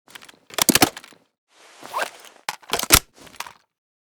g3_reload.ogg